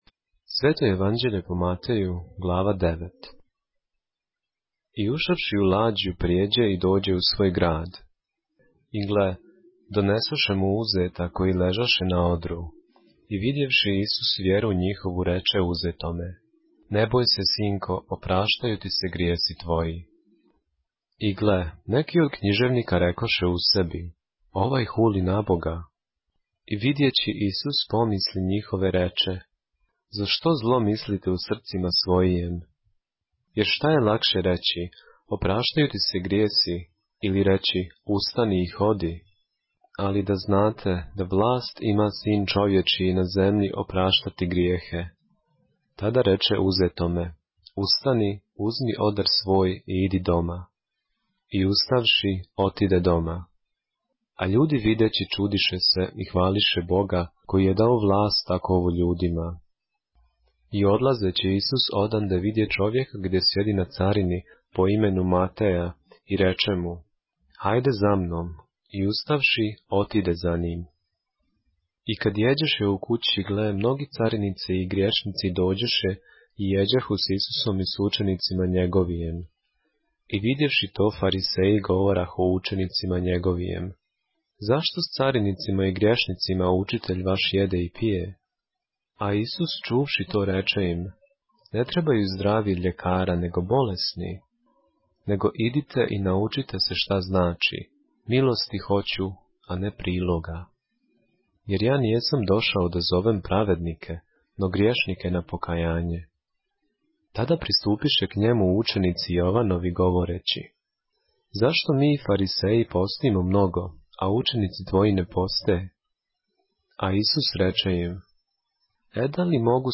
поглавље српске Библије - са аудио нарације - Matthew, chapter 9 of the Holy Bible in the Serbian language